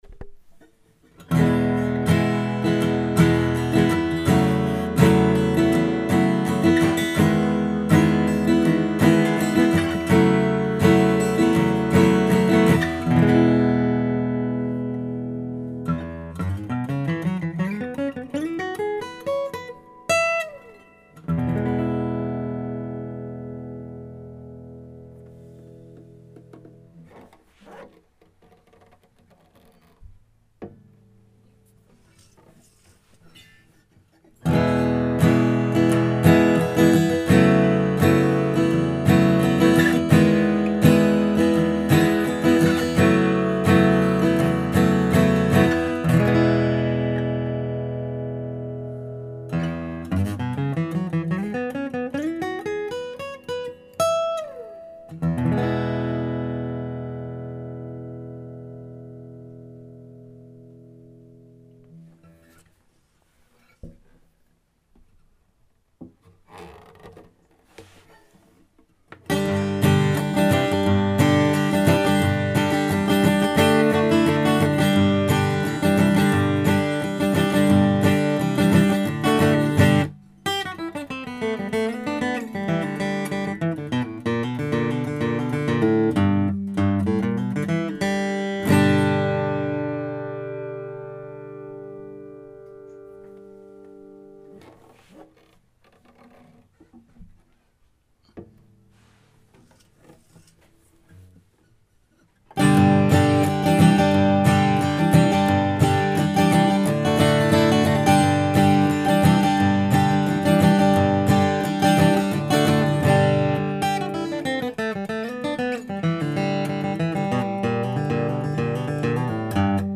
Я бы сказал, они прекрасно дополняют друг друга, не звучат одинаково.
Гревен / Martin D-28 A / B